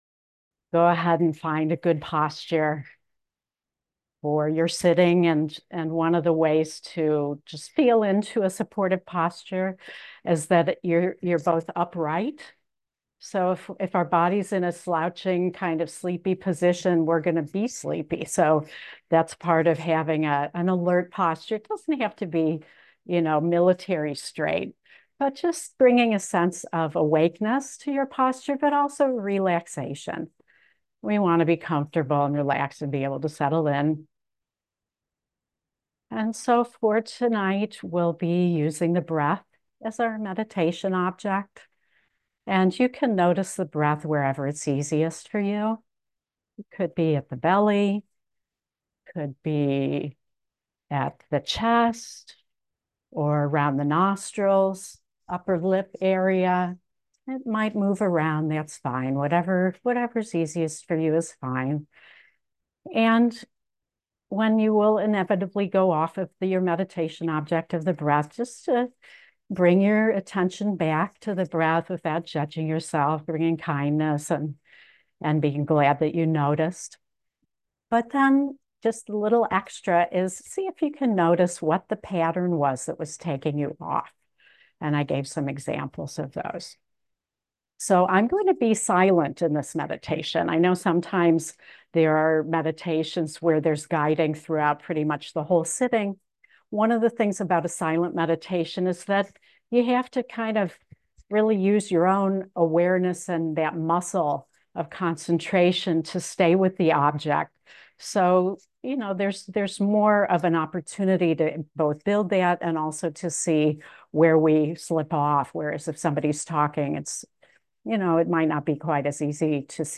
A Meditation on Using the Enneagram as a Tool on the Spiritual Path